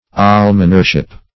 Almonership \Al"mon*er*ship\, n. The office of an almoner.
almonership.mp3